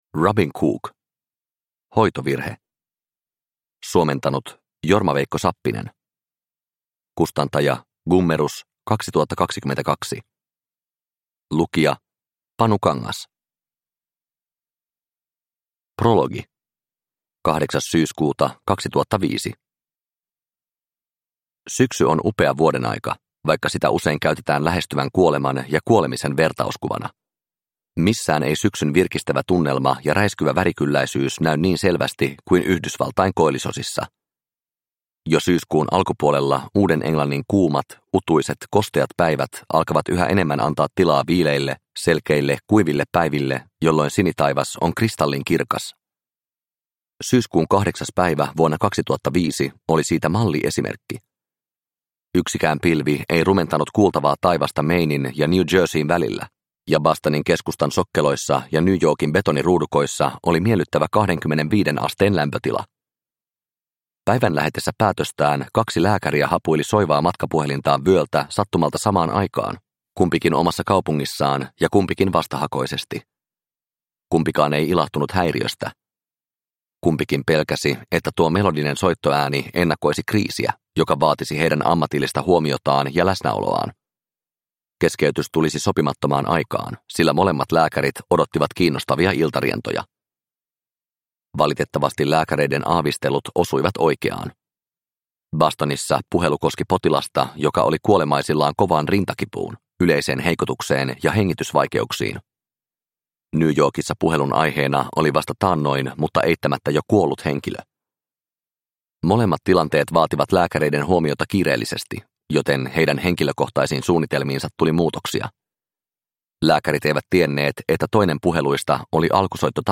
Hoitovirhe – Ljudbok – Laddas ner